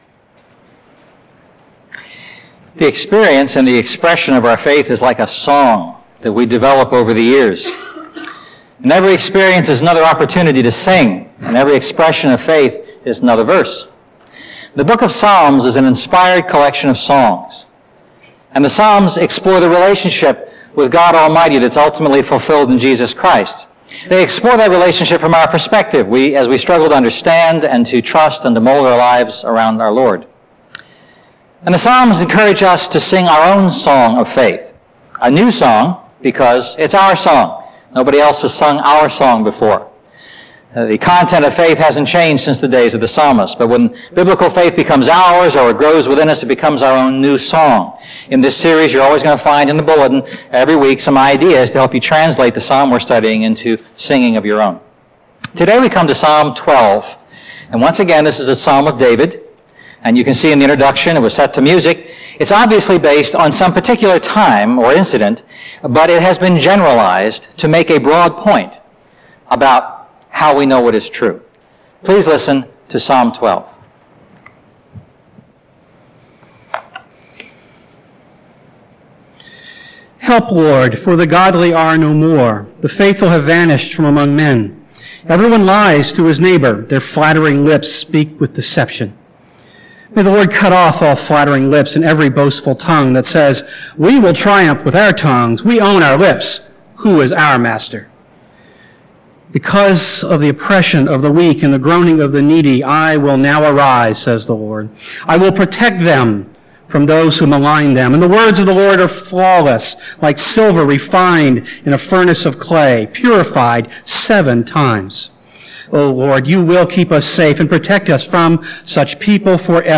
A message from the series "A New Song."